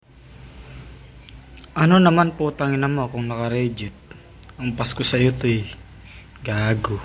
Hahaha Sound Effects Free Download